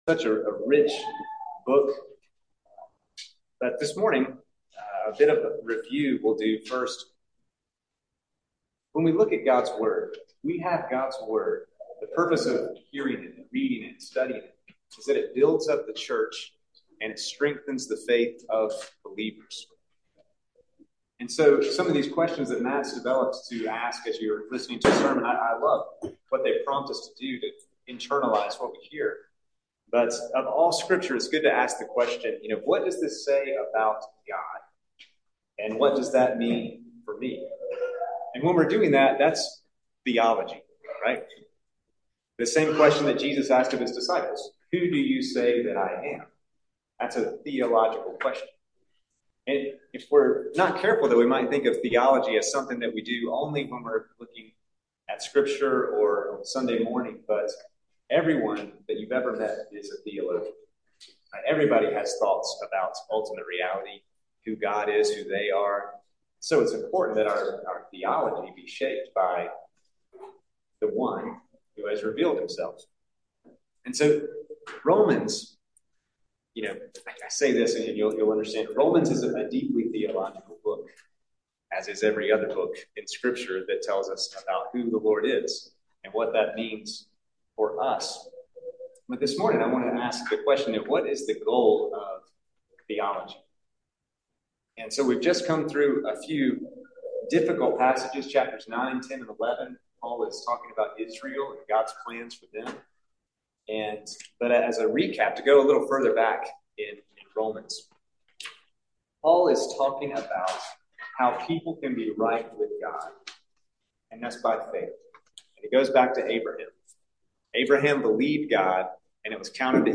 Sermons | Christ the King